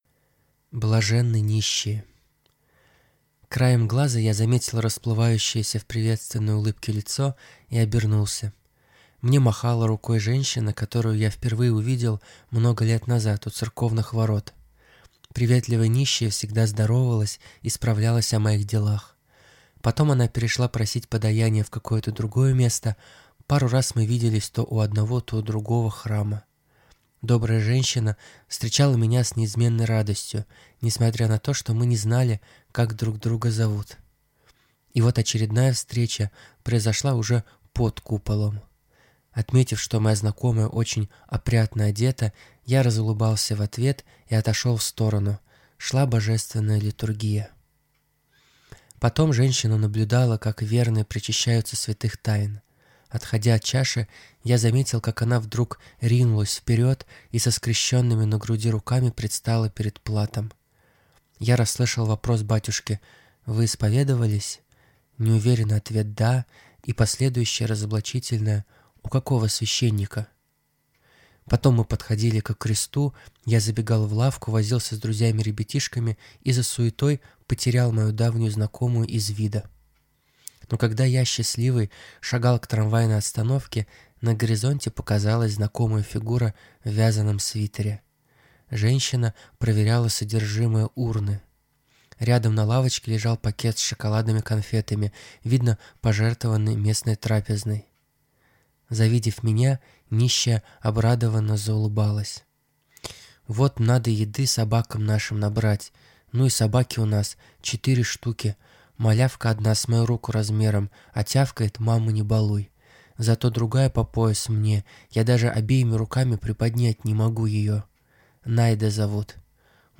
Аудиокнига Пасхальный подарок. Книга для унывающих | Библиотека аудиокниг